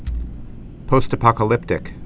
(pōstə-pŏkə-lĭptĭk)